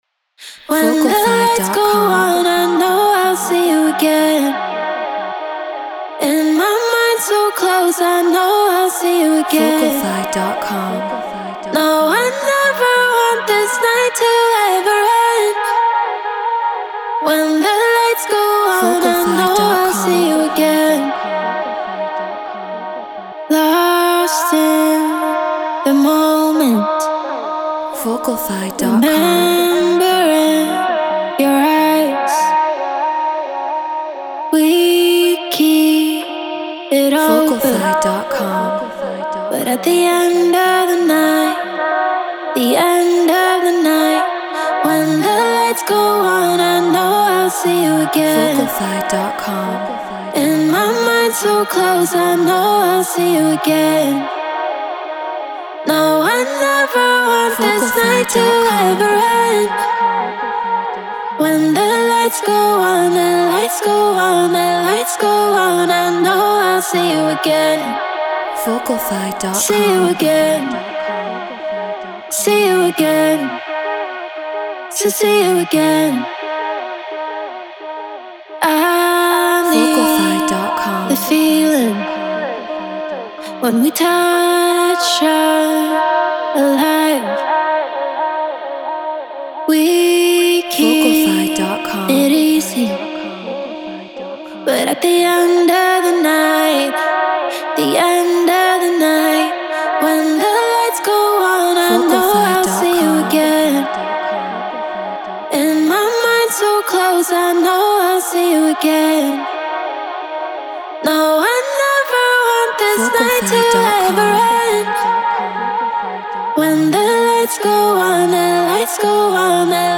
Drum & Bass 174 BPM D#min
Shure KSM 44 Apollo Twin X Pro Tools Treated Room